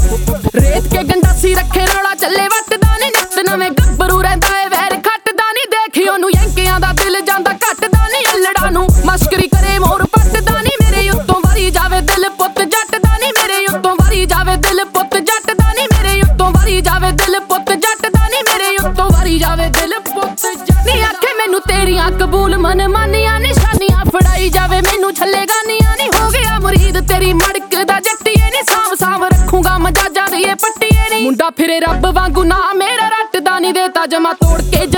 Indian Pop
Жанр: Поп музыка